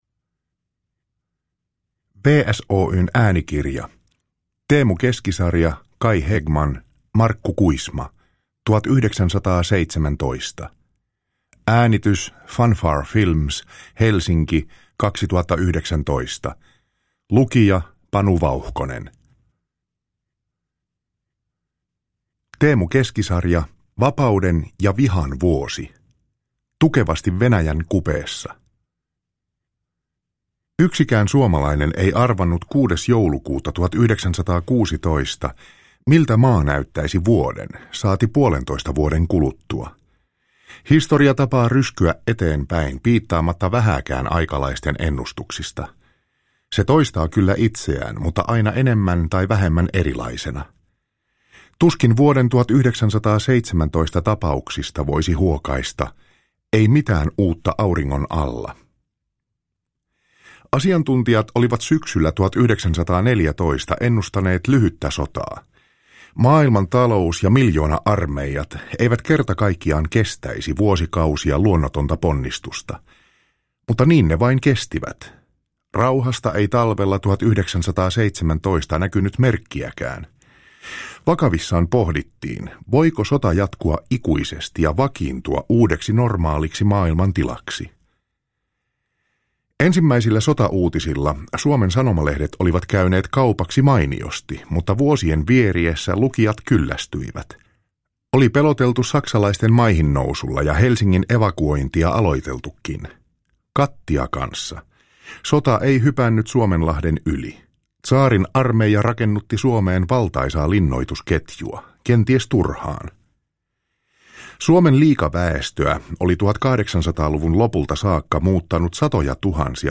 1917 – Ljudbok